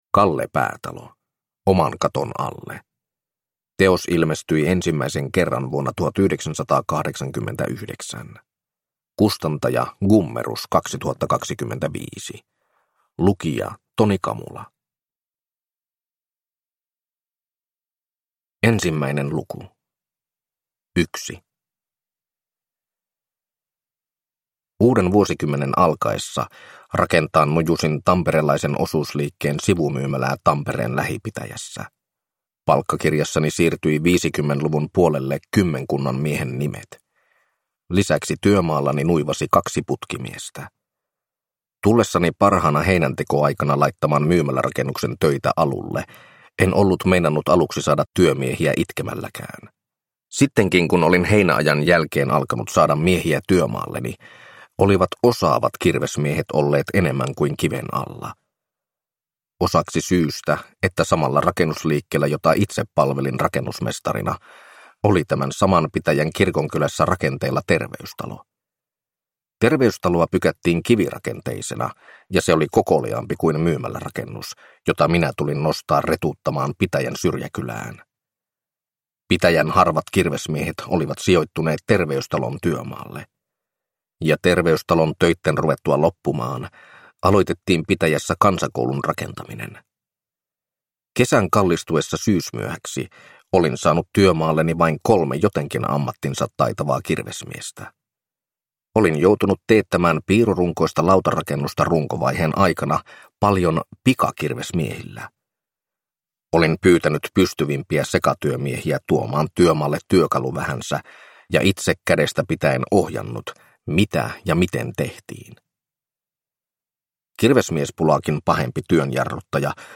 Oman katon alle (ljudbok) av Kalle Päätalo